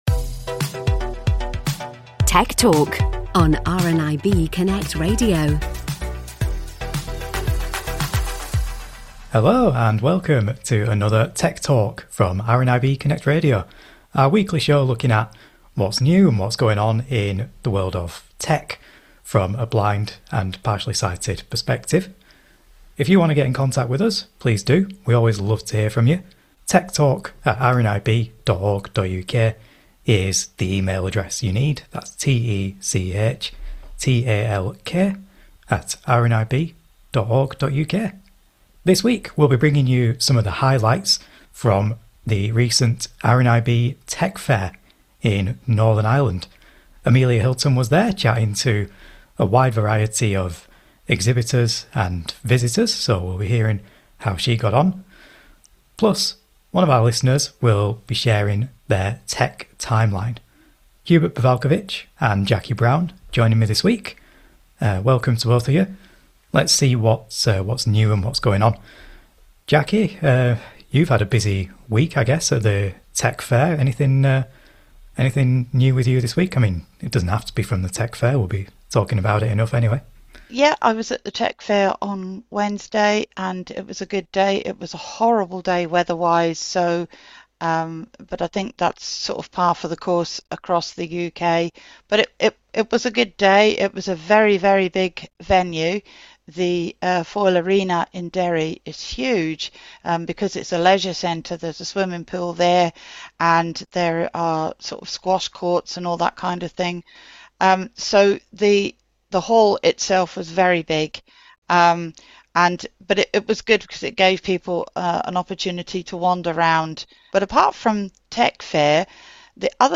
This week we’ll be bringing you some of the highlights from RNIB’s Tech Fair in Northern Ireland.